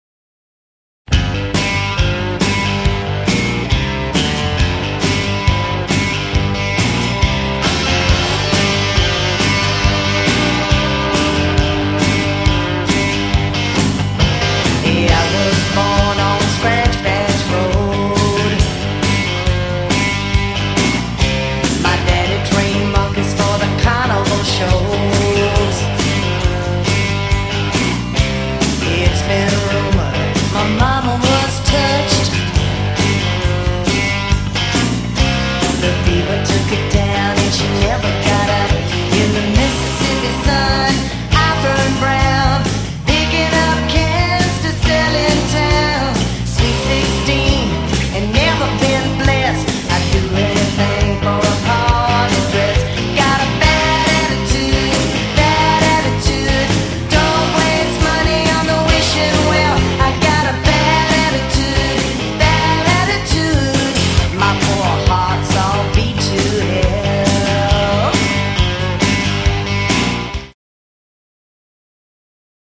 recorded in Austin and Houston,TX